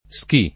ski [ S ki + ] → sci